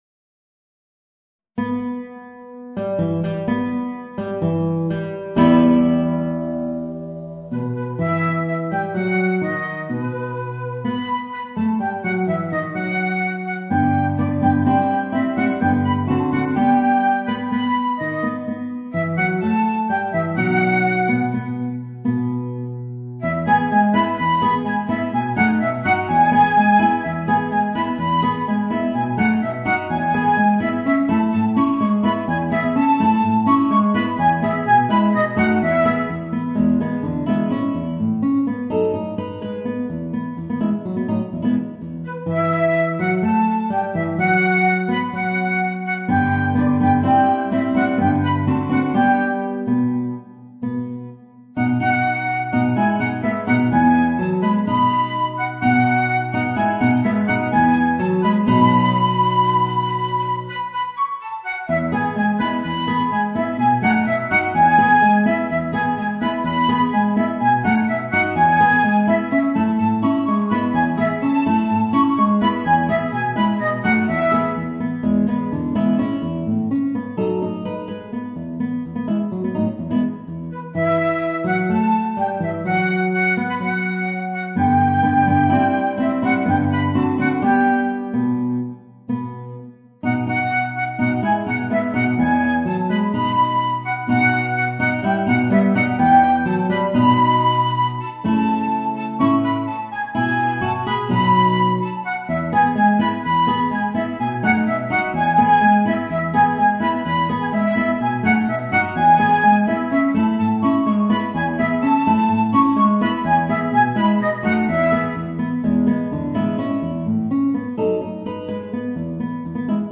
Voicing: Flute and Guitar